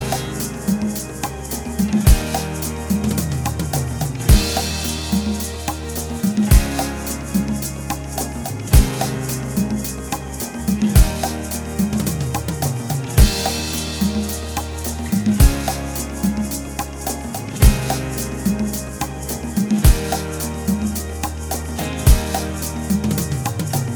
Minus Lead Guitar Rock 6:51 Buy £1.50